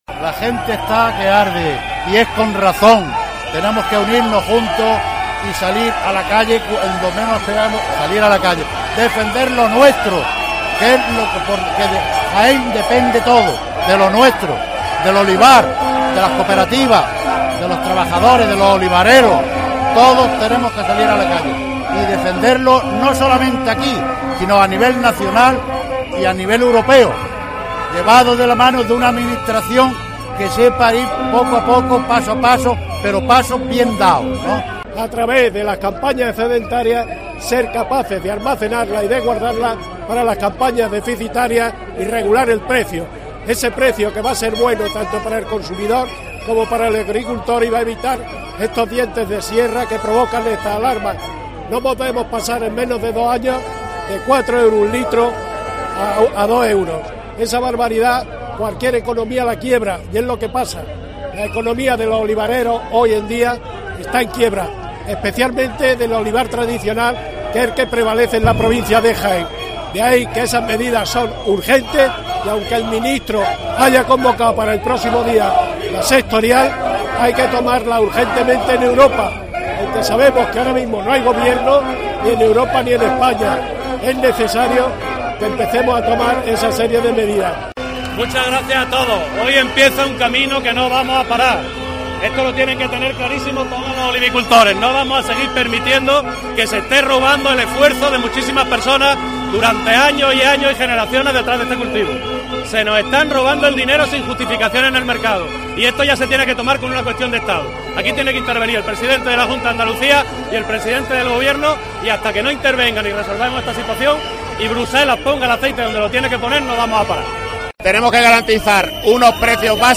Según la Policía Nacional unas 4500 personas se han concentrado ante la Subdelegación del gobineor exigiendo soluciones a la crisis de precios
Manifestación olivarera